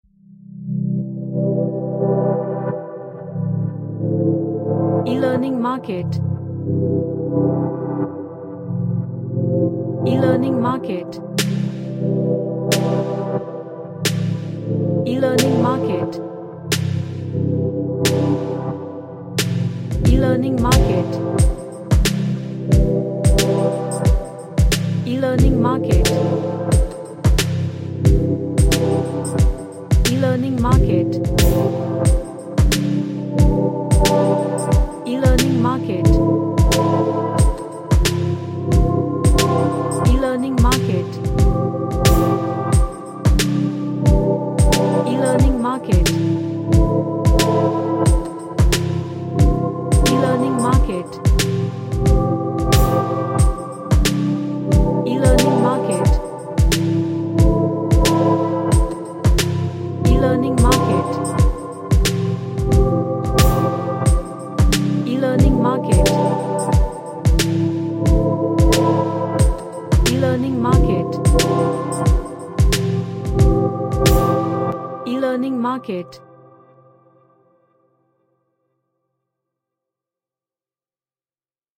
An ambient track with Low pads.
Relaxation / Meditation